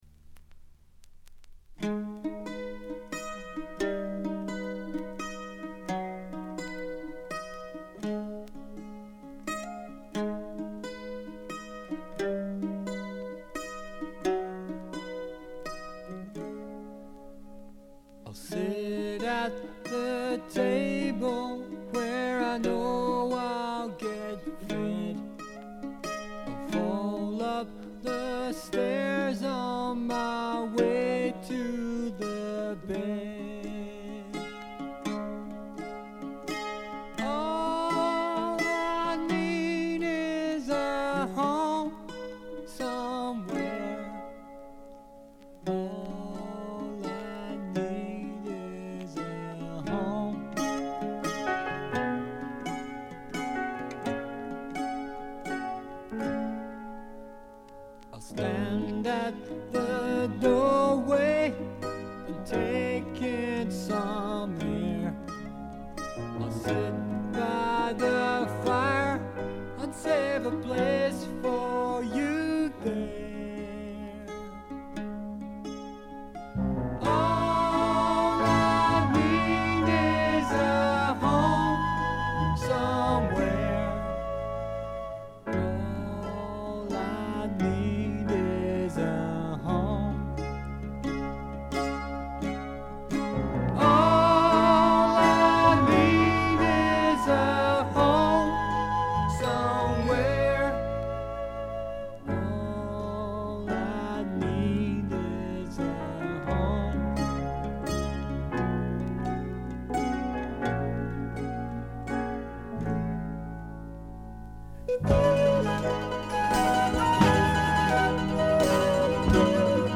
部分試聴ですが、ほとんどノイズ感無し。
試聴曲は現品からの取り込み音源です。